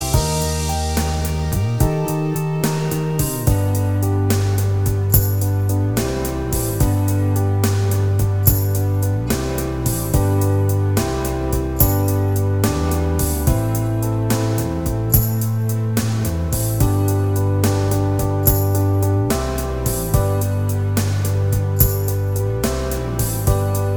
Two Semitones Down Pop (1970s) 5:00 Buy £1.50